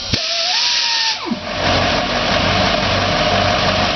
start_up.wav